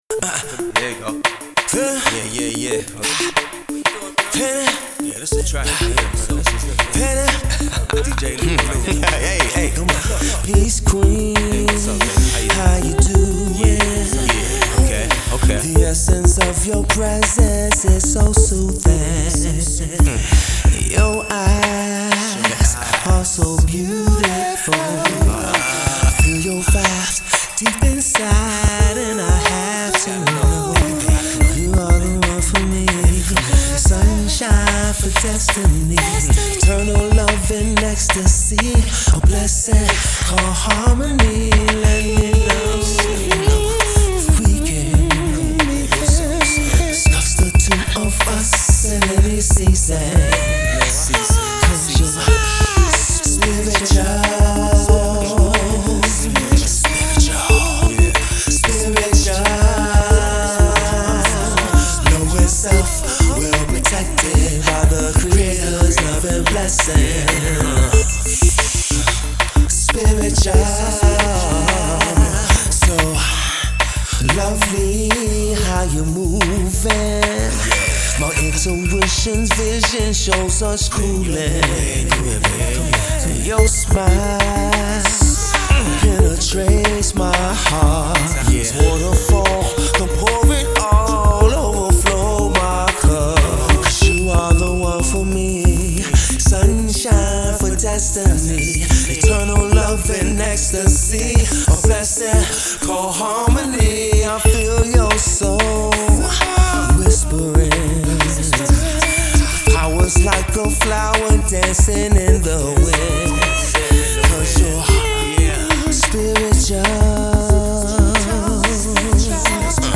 New Neo Soul R&B Heat for the ladies